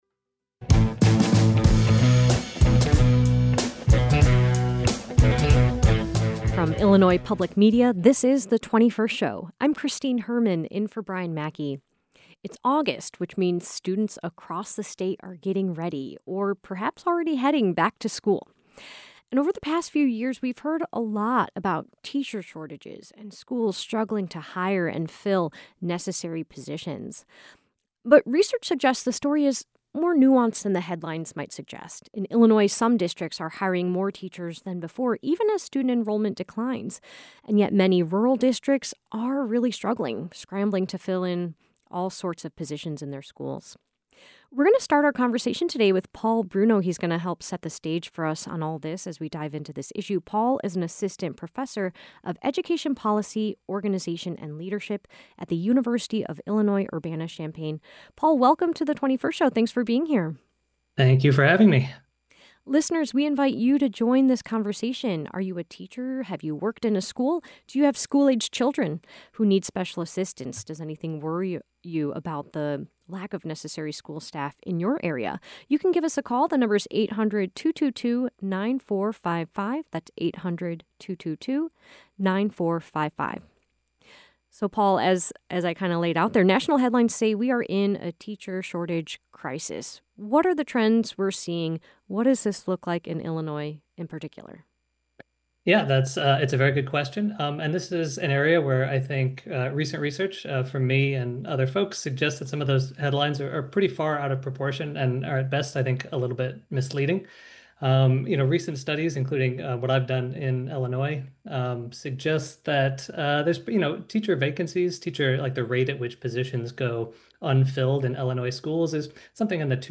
A panel of educators and education policy experts join today's conversation.